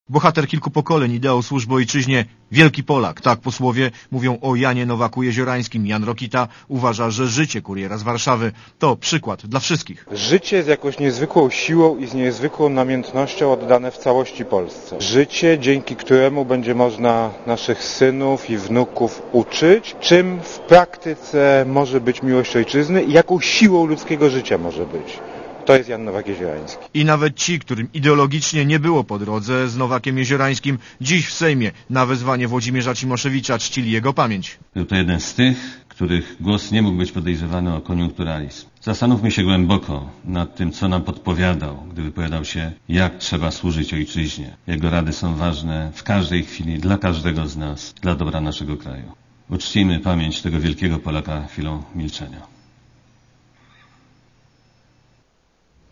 Piątkowe obrady Sejm RP zaczął od uczczenia pamięci Nowaka-Jeziorańskiego.